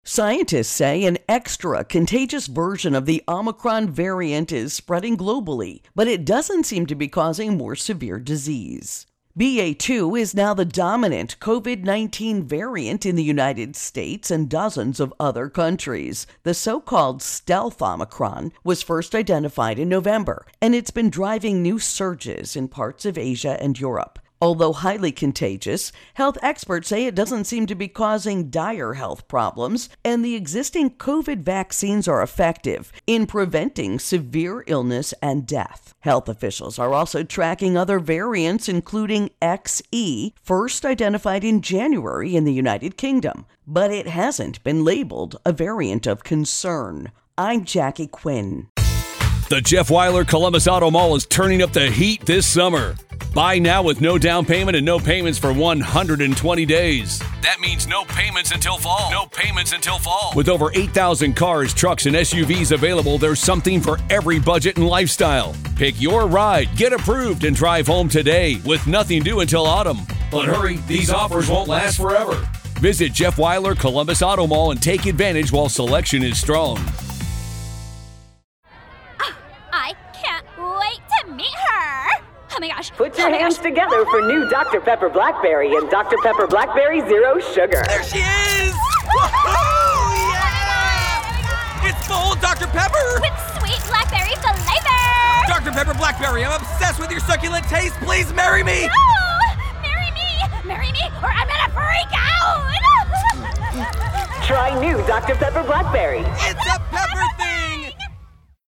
Virus Viral Questions Variants Intro and Voicer